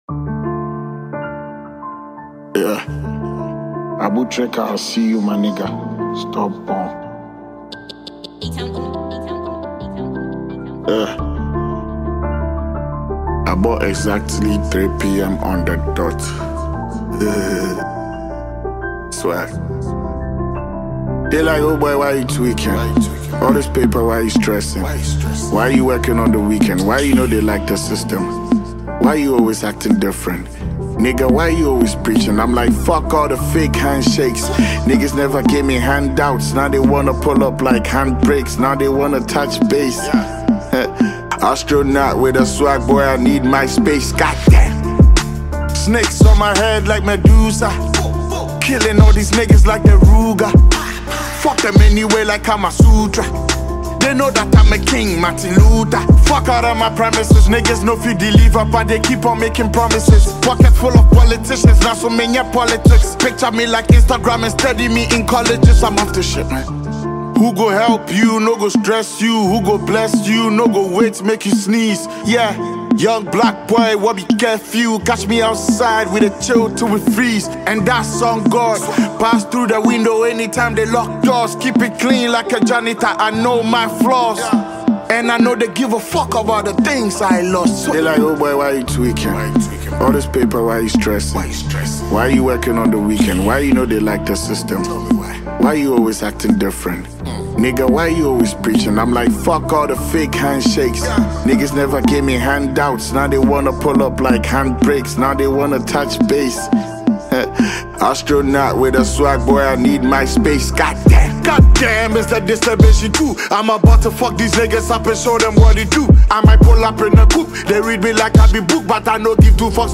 Ghanaian award-winning rapper
Genre: Hip-Hop / Rap